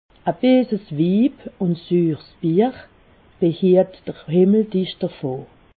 Haut Rhin
Ville Prononciation 68
Ribeauvillé